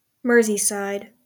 Merseyside (/ˈmɜːrzisd/
MUR-zee-syde) is a ceremonial and metropolitan county in North West England.